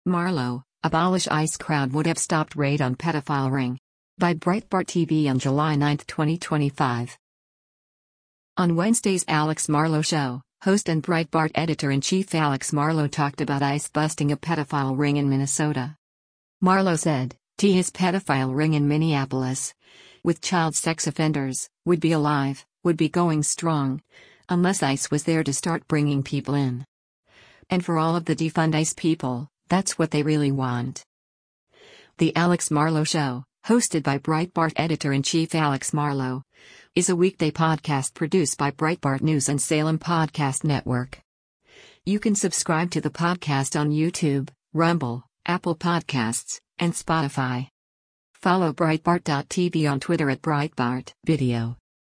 On Wednesday’s “Alex Marlow Show,” host and Breitbart Editor-in-Chief Alex Marlow talked about ICE busting a pedophile ring in Minnesota.
“The Alex Marlow Show,” hosted by Breitbart Editor-in-Chief Alex Marlow, is a weekday podcast produced by Breitbart News and Salem Podcast Network.